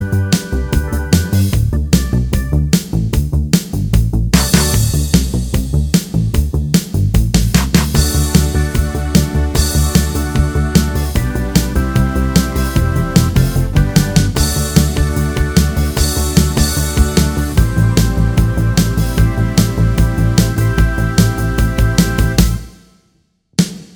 Minus All Guitars Pop (1980s) 3:55 Buy £1.50